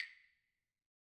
Percussion
Claves1_Hit_v2_rr2_Sum.wav